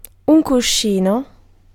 Ääntäminen
IPA : /ˈpɪ.ləʊ/